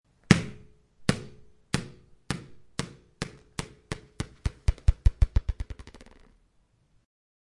Download Ball sound effect for free.
Ball